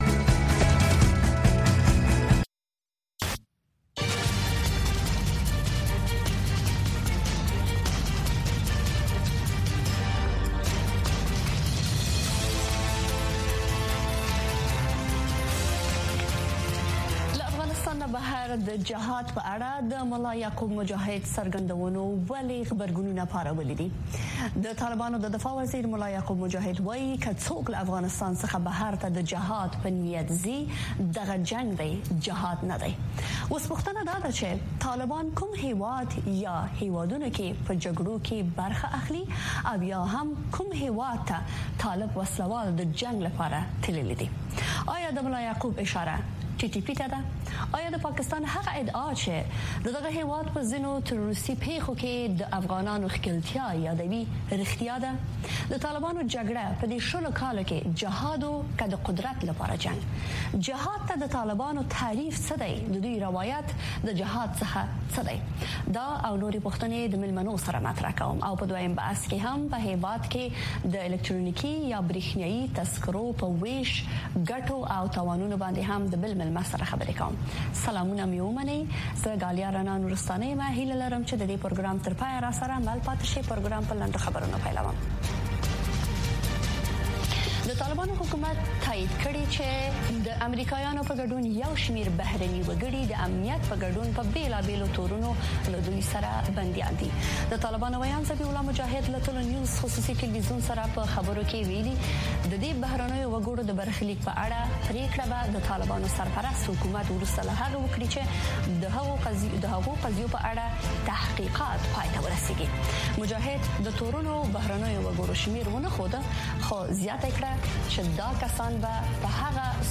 په دې خپرونه کې د چارواکو، شنونکو او خلکو سره، مهمې کورنۍ او نړیوالې سیاسي، اقتصادي او ټولنیزې مسئلې څېړل کېږي. دغه نیم ساعته خپرونه له یکشنبې تر پنجشنبې، هر مازدیګر د کابل پر شپږنیمې بجې، په ژوندۍ بڼه خپرېږي.